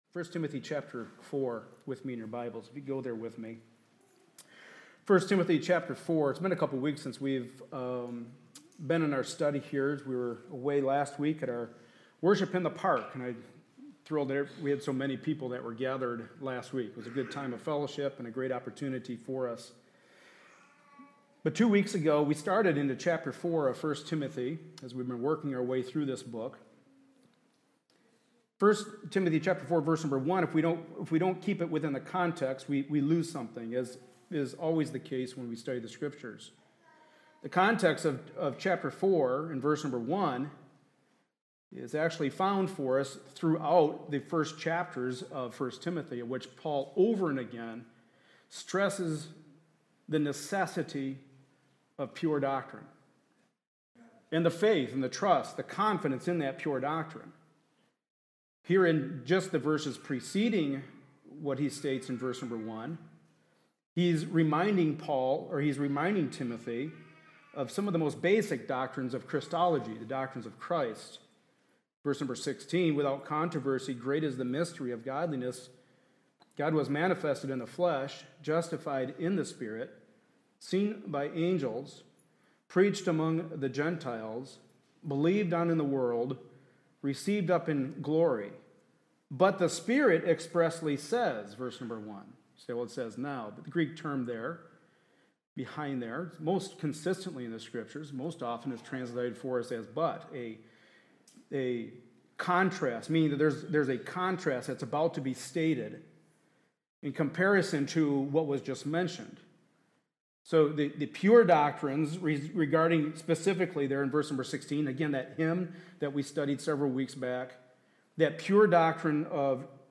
1 Timothy 3:14-4:5 Service Type: Sunday Morning Service A study in the Pastoral Epistles.